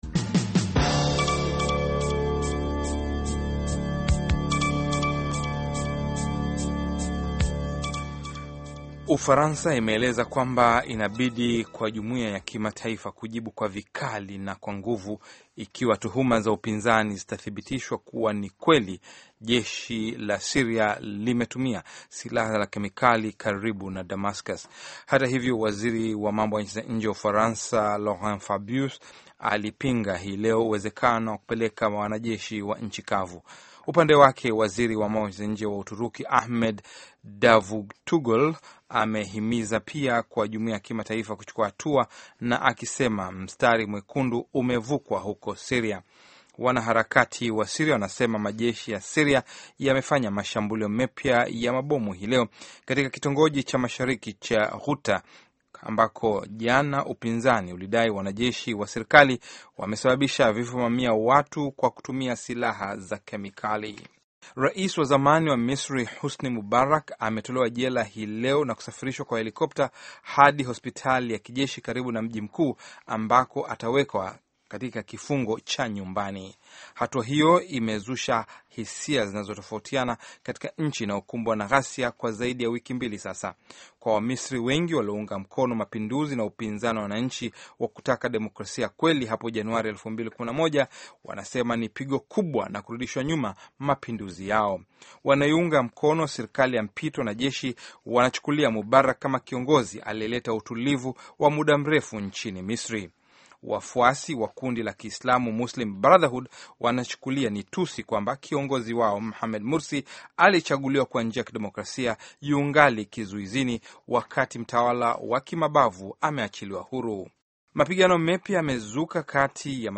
Taarifa ya Habari VOA Swahili - 6:48